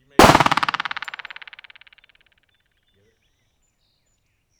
Extreme Flutter Echo taken at a pistol shooting range.
The flutters are about 55 ms apart, giving an almost reverberant impression, but the stepped Schroeder curve reveals that this is definitely not reverberation (Fig. 2).
Normally the signal-to-noise ratio for this method is quite low, but in the case of a pistol shot the signal level is very high and produces a good IR.